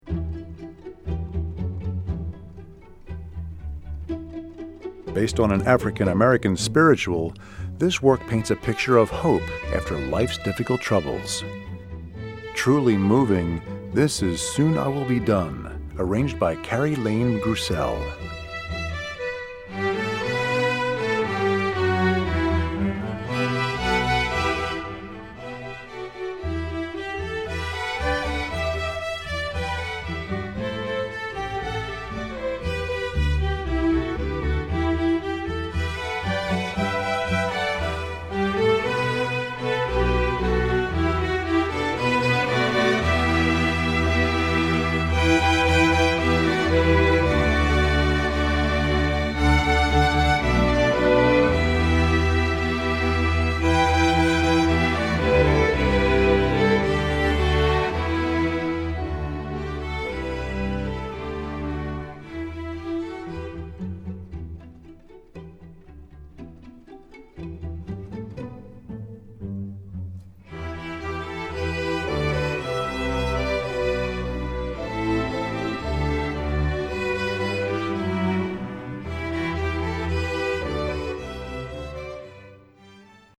Composer: African-American Spiritual
Voicing: String Orchestra